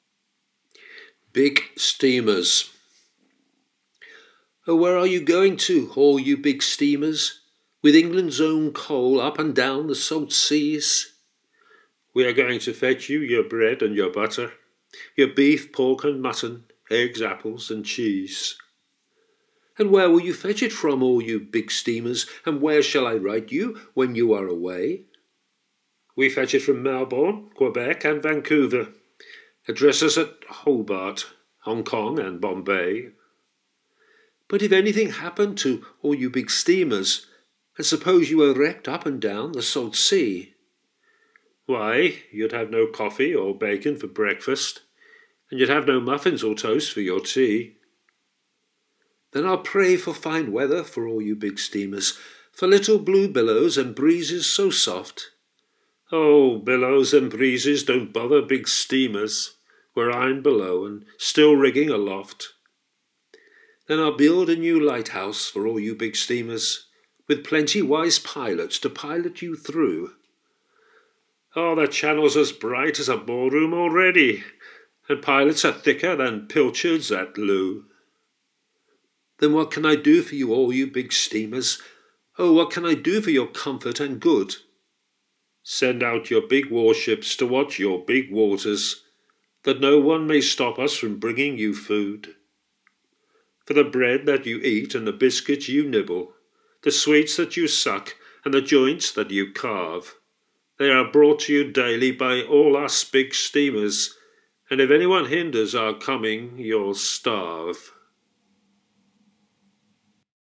Reading Aloud